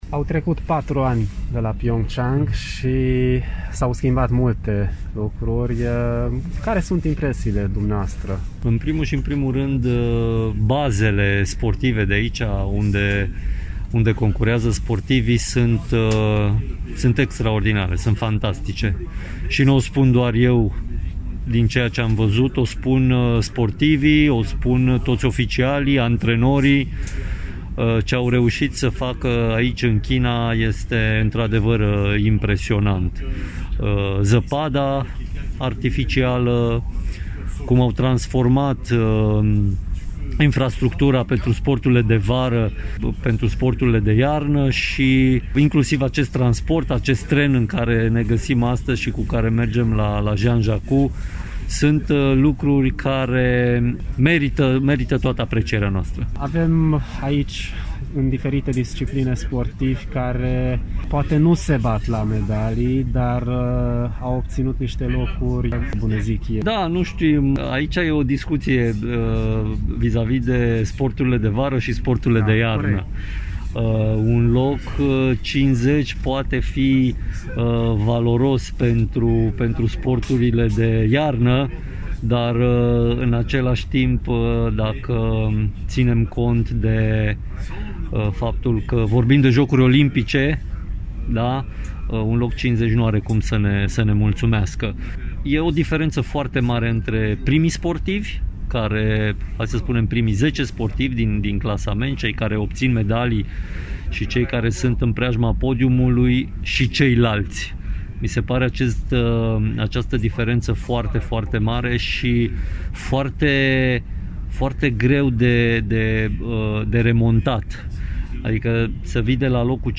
Un interviu audio cu președintele Comitetului Olimpic și Sportiv Român, Mihai Covaliu